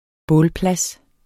Udtale [ ˈbɔːl- ]